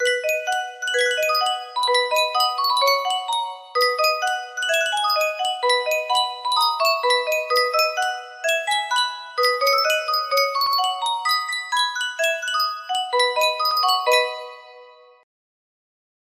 Yunsheng Music Box - Keep On the Sunny Side 6186 music box melody
Full range 60